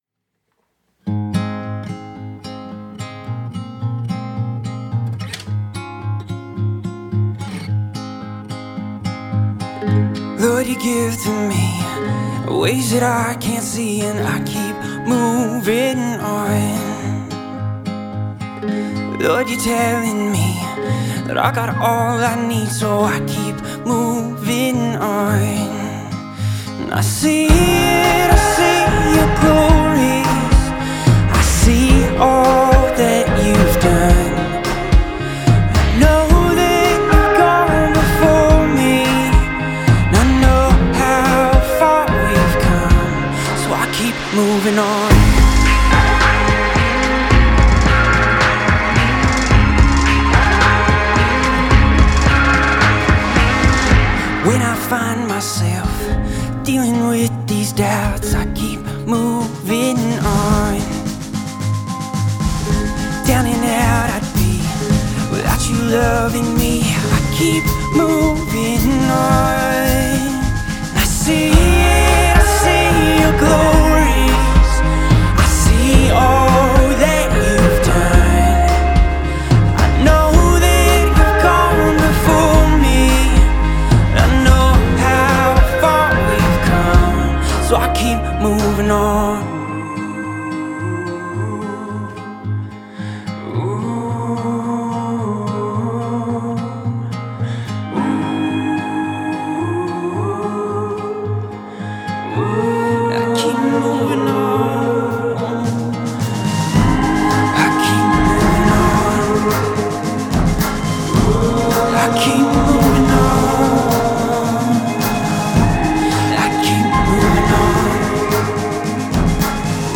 299 просмотров 56 прослушиваний 6 скачиваний BPM: 109